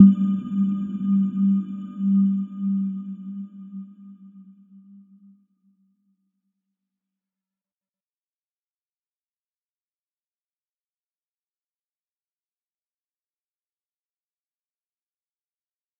Little-Pluck-G3-f.wav